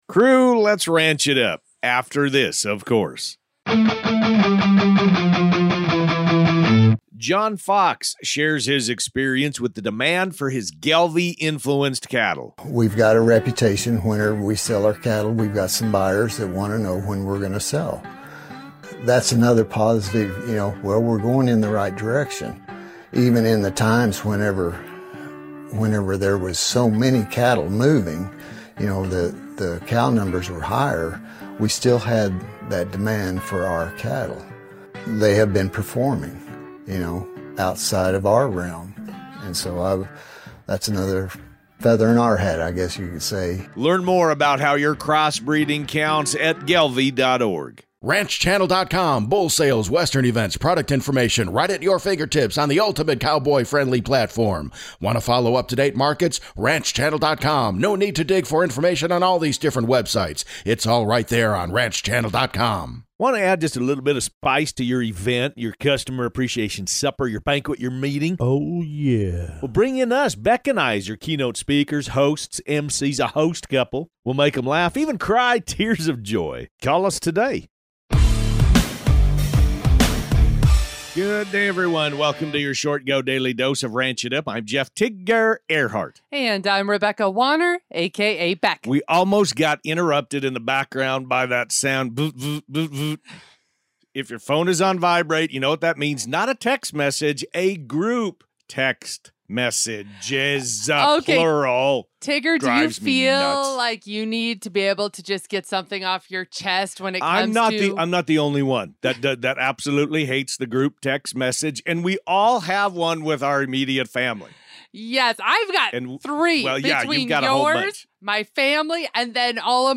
They're serving up the most important ranch-related headlines, from new flavor drops and condiment controversies to the best pairings and fan favorites. Expect insightful (and hilarious) commentary, listener shout-outs, and everything you need to stay in the loop on all things ranch.